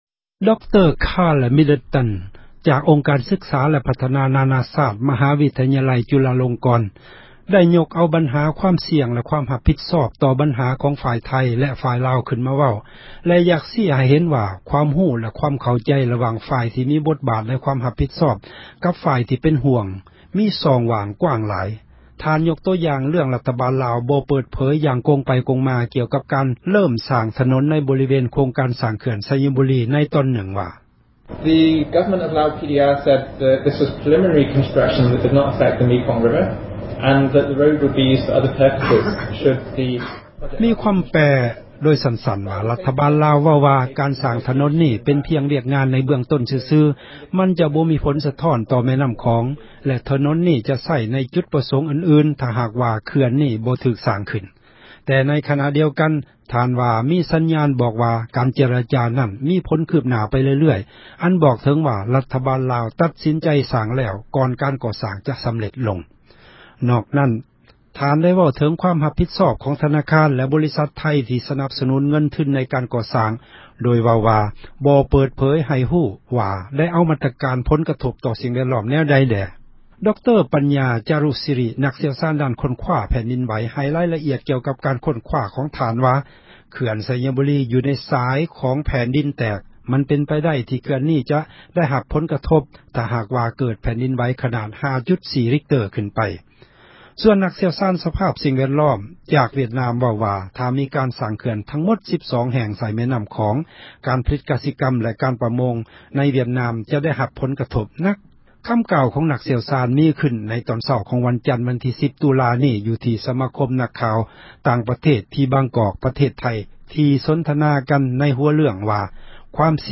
ຄໍາກ່າວ ຂອງ ນັກຊ່ຽວຊານ ມີຂື້ນ ໃນຕອນເຊົ້າ ຂອງ ວັນຈັນ ວັນທີ 10 ຕຸລາ ນີ້ ຢູ່ທີ່ ສະມາຄົມ ນັກຂ່າວ ຕ່າງປະເທດ ທີ່ບາງກອກ ປະເທດໄທ ທີ່ ສົນທະນາກັນ ໃນຫົວເຣື້ອງ: ຄວາມສ່ຽງ ແລະ ຄວາມ ຮັບຜິດຊອບ ຂອງ ຣັຖບານໄທ, ທະນາຄານ ແລະ ບໍຣິສັດ ຫລາຍແຫ່ງ ຂອງໄທ ທີ່ໃຫ້ ເງິນກູ້ຢືມ, ຄວາມຮັບ ຜິດຊອບ ຂອງ ຣັຖບານລາວ, ແລະ ກັມມາທິການ ແມ່ນໍ້າຂອງ MRC ຜູ້ອໍານວຍ ຄວາມສະດວກ ໃນການ ຕັດສີນໃຈ.